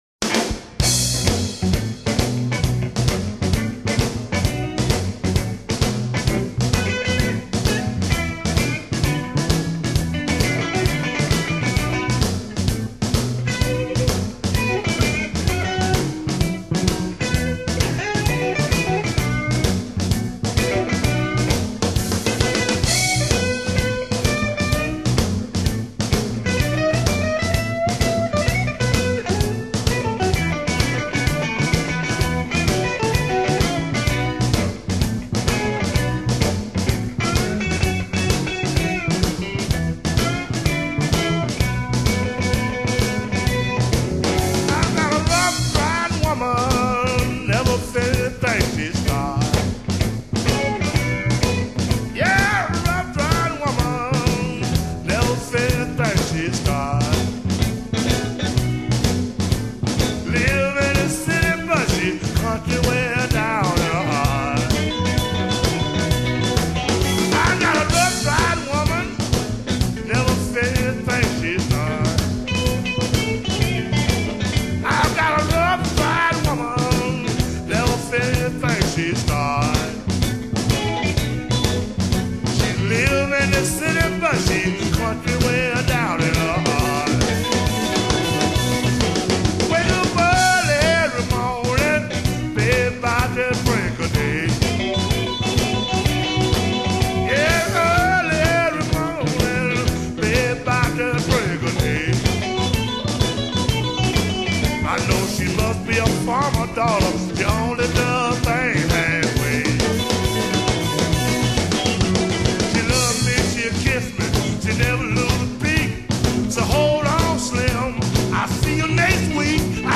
vocals, guitar
bass, vocals
drums